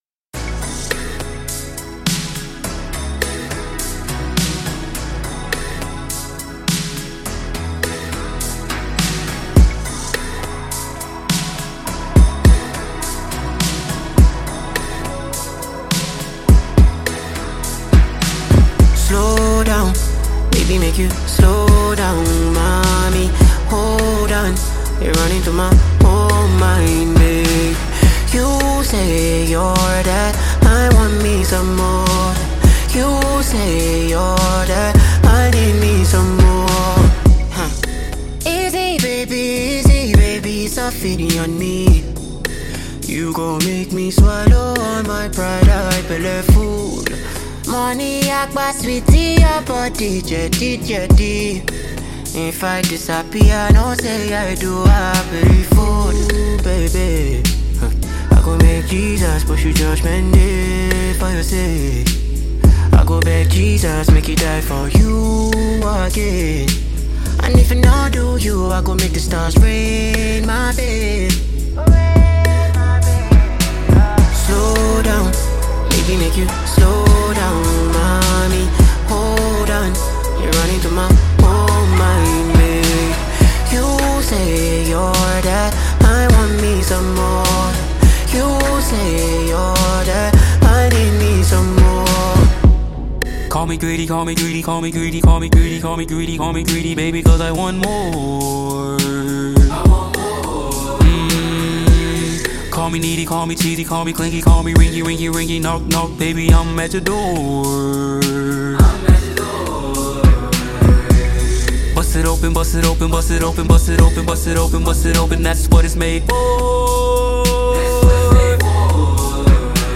African Music Genre: Afrobeats Released